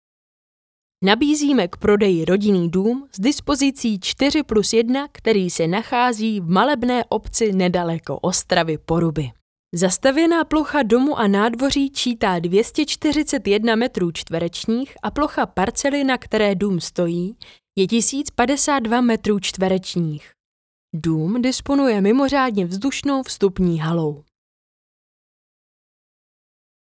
Ženský voiceover do reklamy / 90 sekund
Hledáte do svého videa příjemný ženský hlas?
Nahrávání probíhá v profesionálním studiu a výsledkem je masterovaná audio stopa ve formátu WAV, ořezaná o nádechy a další rušivé zvuky.